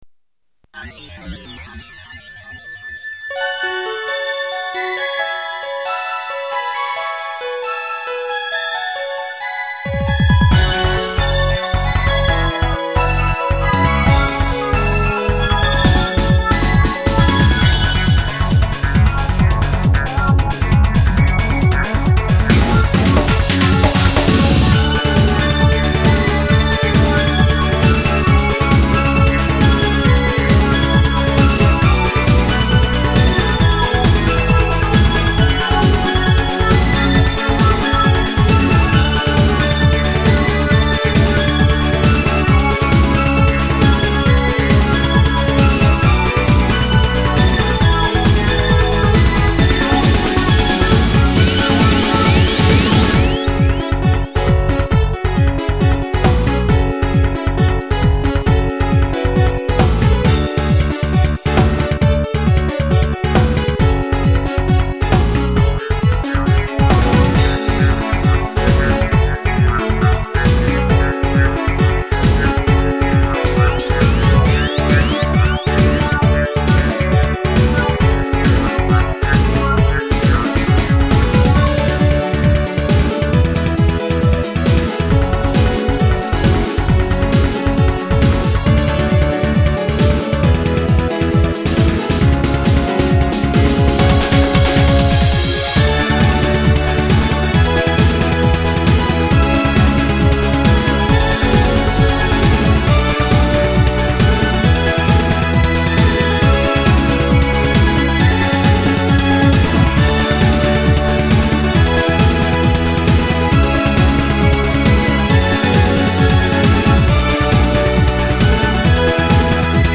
SoundEngine   Cherry Techno
ここでも少し趣を変えて、テクノ、トランス系の曲です。
2音源での録音やフィルター等、実験的な要素も多く含まれています。